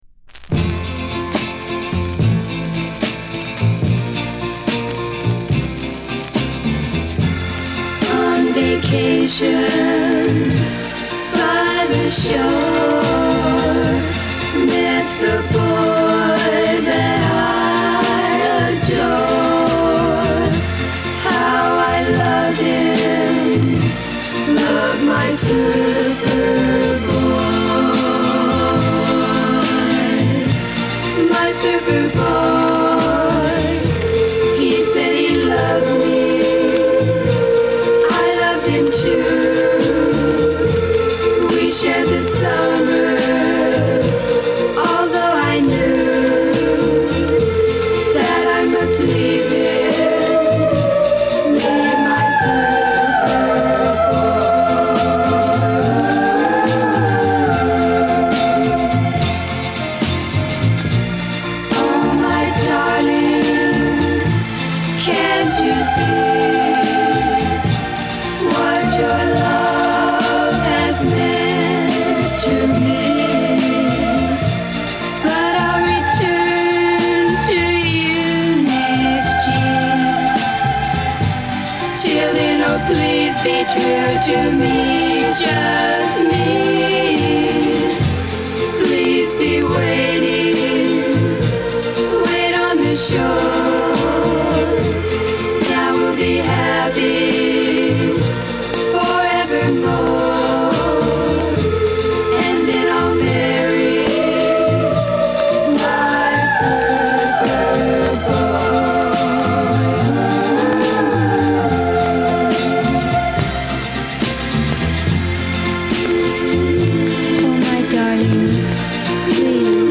B面はバラード調の美しいガールズ・サウンドを真空パック。こちらも聴き惚れてしまうほどにピュアで美しい。
バックグラウンドノイズはいります。